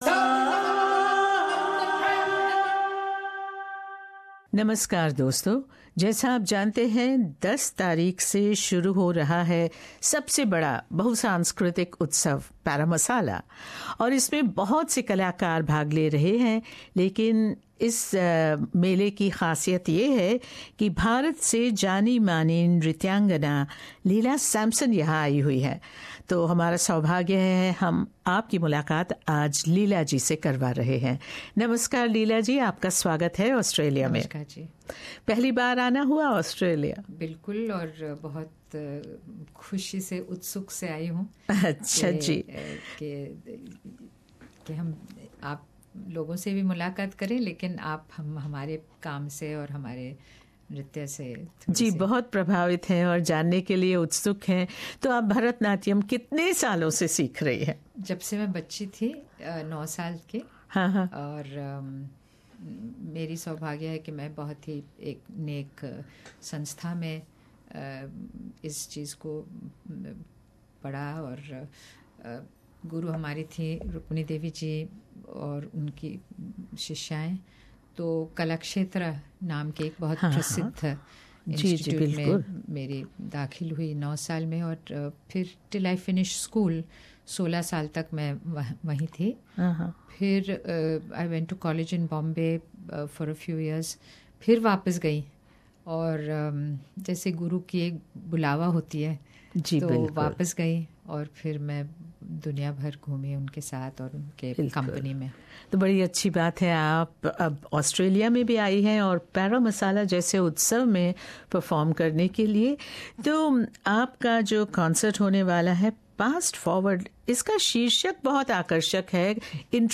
Internationally acclaimed dancer, teacher, writer and choreographer of bharata natyam Leela Samson and her group Spanda is performing in Parramasal 2017. In an exclusive interview with SBS Hindi Padmashree Ms Samson shared her philosophy and motivations that keep her dancing.